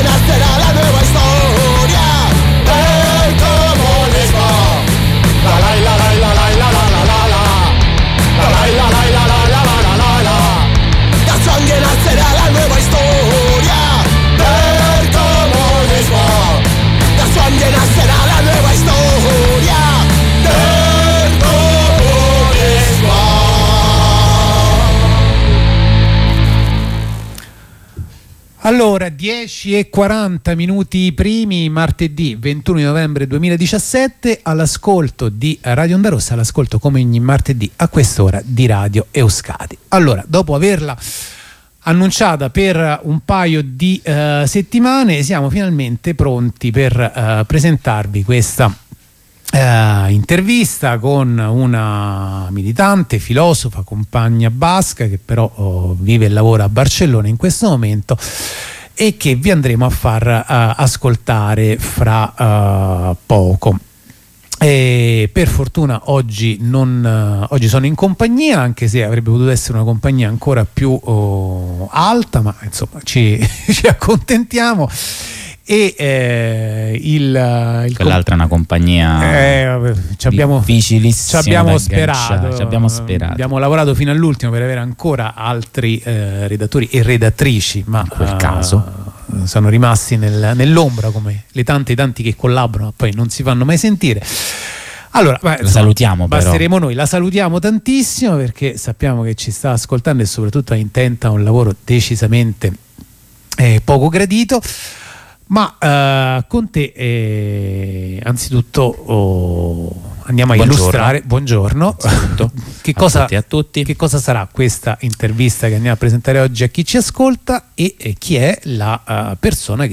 L'intervista tradotta in italiano
L'intervista tradotta in italiano Durata 25m 1s La trasmissione completa, con l'introduzione e la postfazione da parte dei redattori di radio Euskadi Durata 45m 1s L'intervista in lingua originale Durata 25m 10s